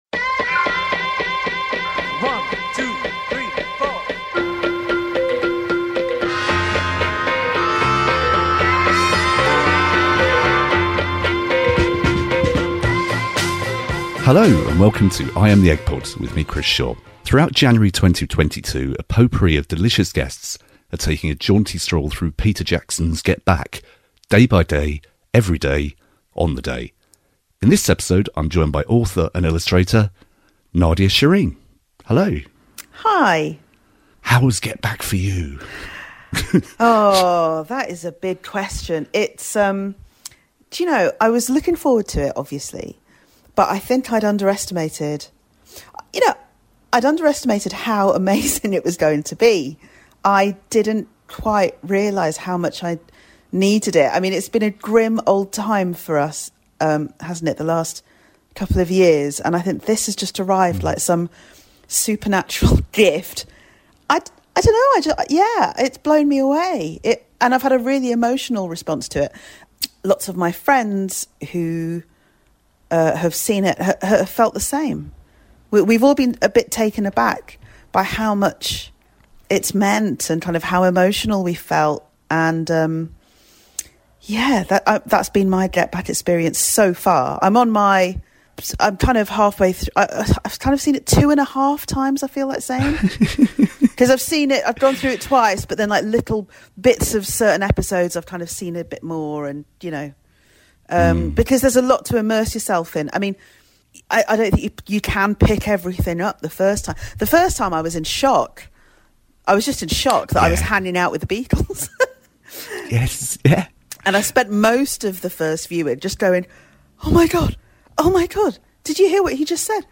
On 26th October 2023 it was announced that The Beatles were releasing a new song, Now and Then, as well as remixed - and expanded - Red and Blue albums. In a live interactive online stream